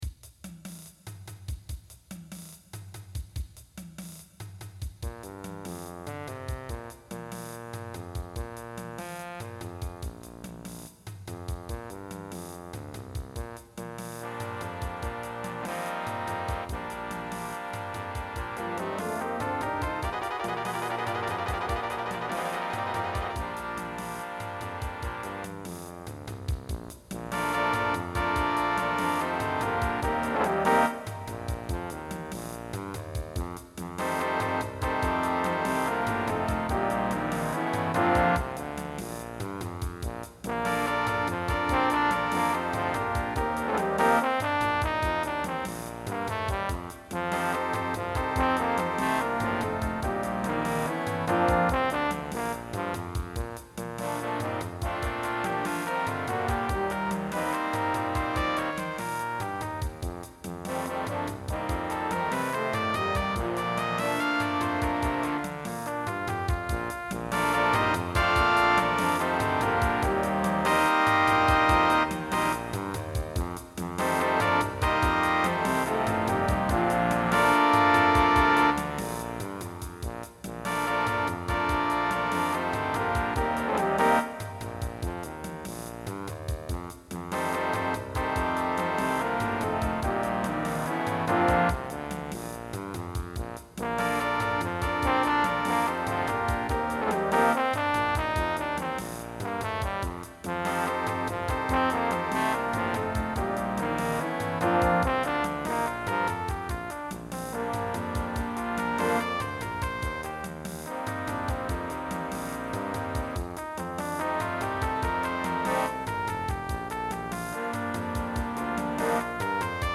chilling out; music for a late summer evening